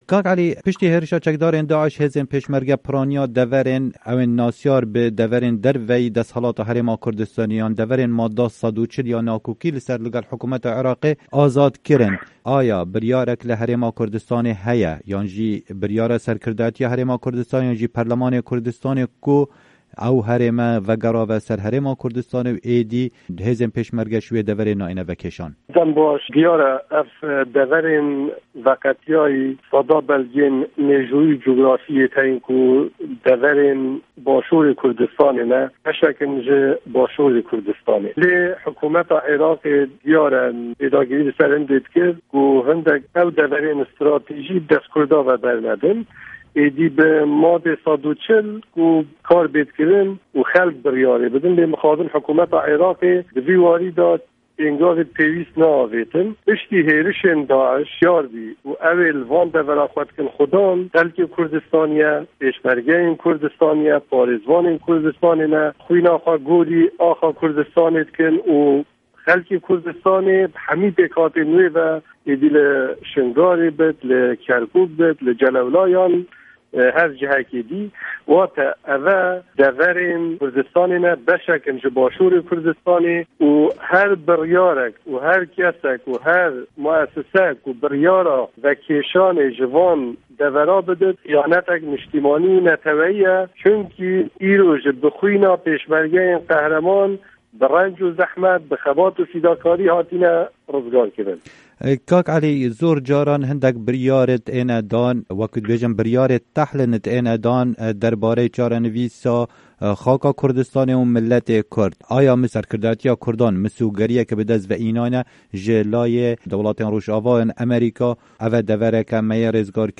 Di hevpeyvînekê de ligel Dengê Amerîka, Alî Alî Halo, endamê parlemana Herêma Kurdistanê dibêje, çi kesê ku ji van deveran vekêşe ewê wekî xayîn were dîtin.
Hevpeyvîn bi Alî Alî Halo re